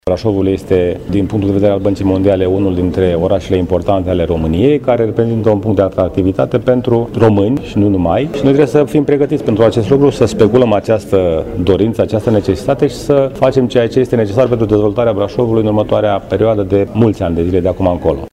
La nivelul municipiului, asistența din partea Băncii Mondiale este importantă prin calitatea specialiștilor și a expertizei de care va beneficia Brașovul, pentru dezvoltarea în perspectivă, după cum a explicat primarul George Scripcaru: